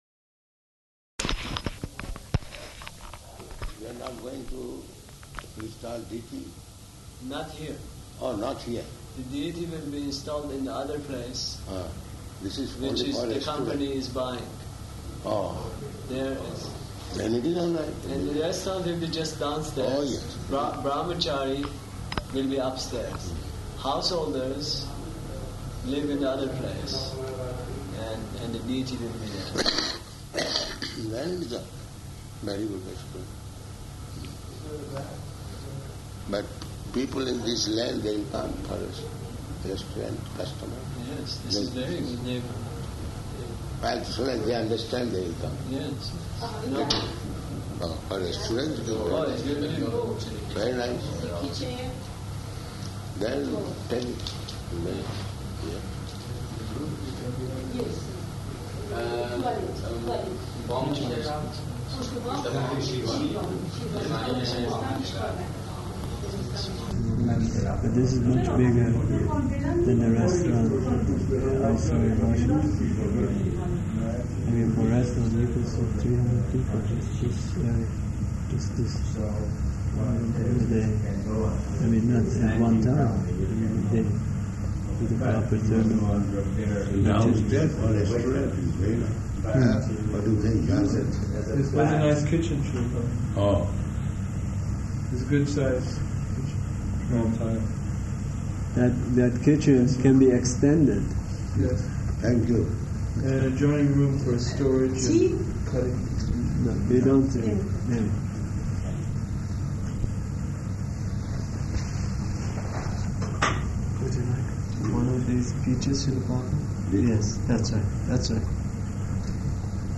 Location: Tehran